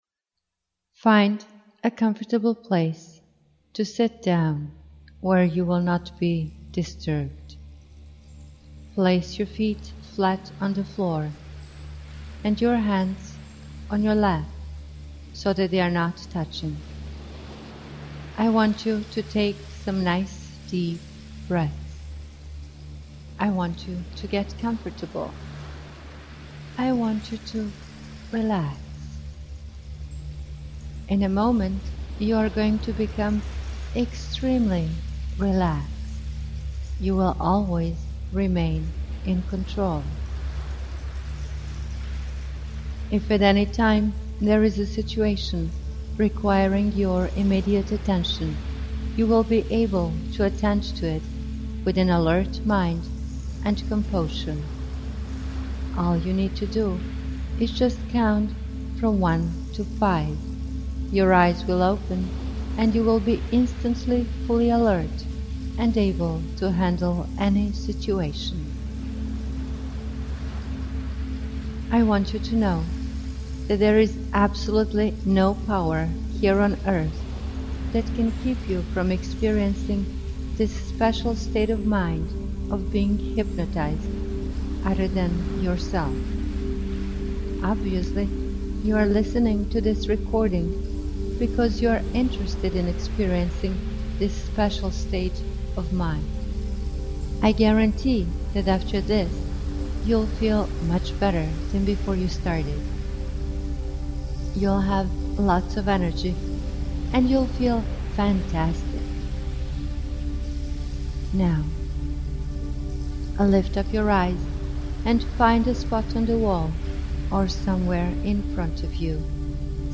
Tags: Science & Nature Hypnosis Hypnosis recording Therapy Hypnosis Audio Clips